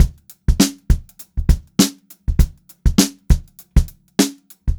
100STBEAT3-L.wav